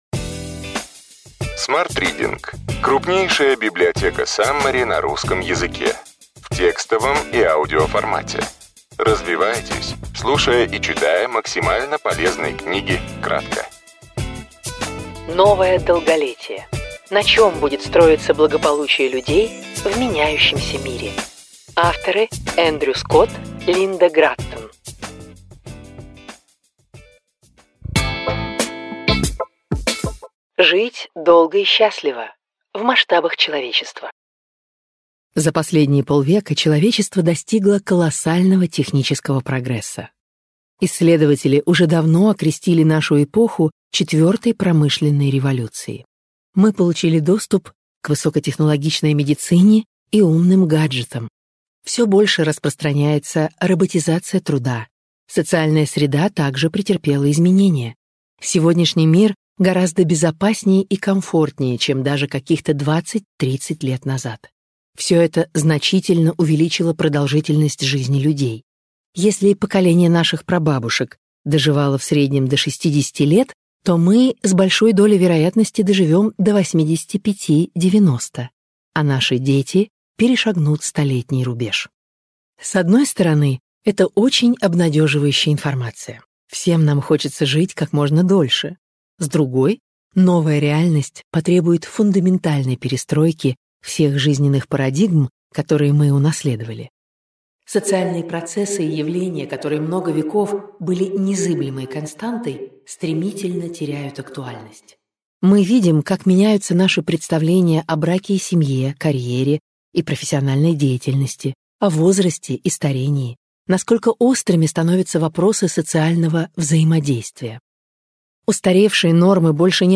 Аудиокнига Ключевые идеи книги: Новое долголетие.